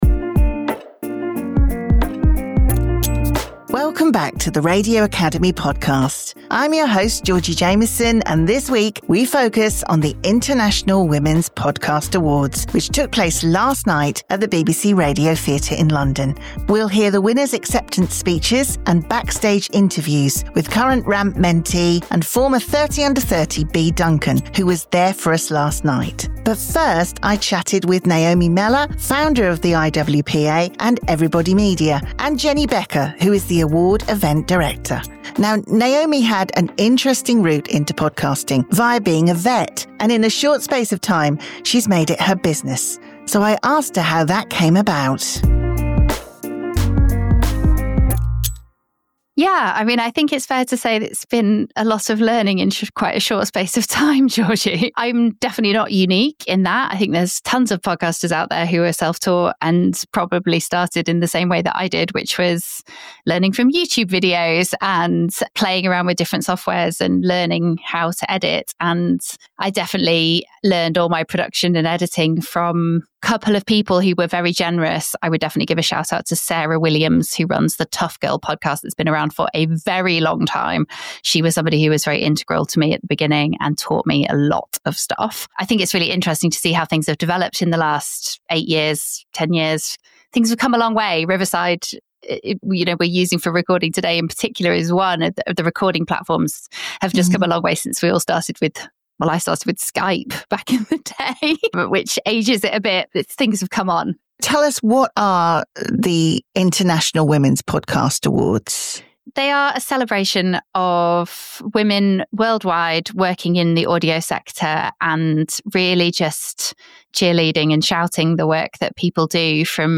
This week’s podcast comes from last night’s International Women’s Podcast Awards, which took place at the BBC Radio Theatre in London.